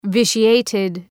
Προφορά
{‘vıʃı,eıtıd}